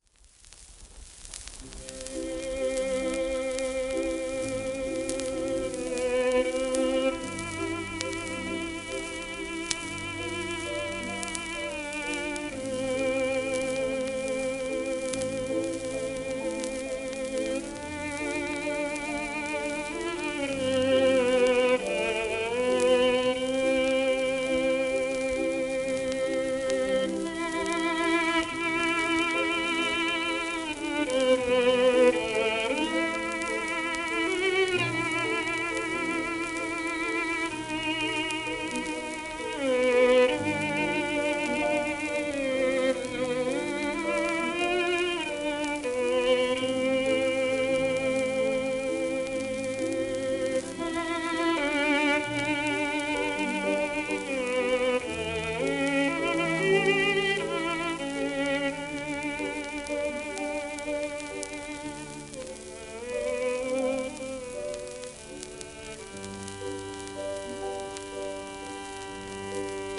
神童時代の録音